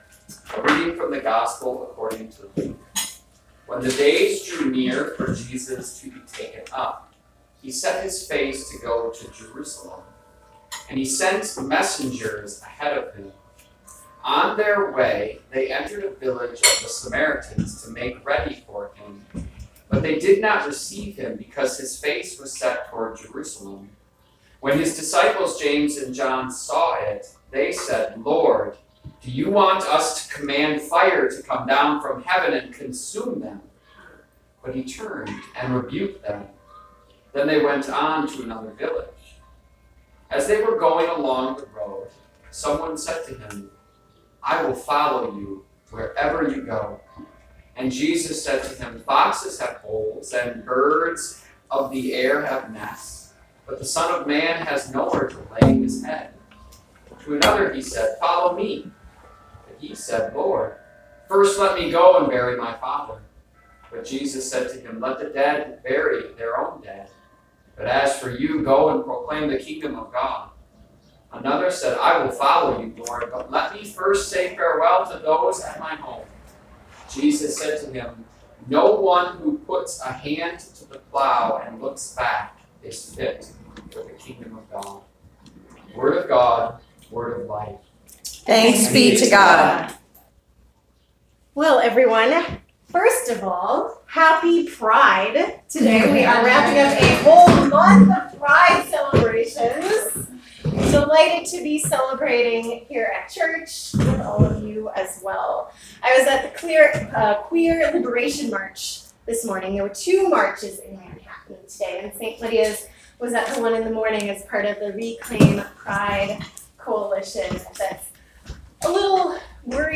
June 30, 2019 Sermon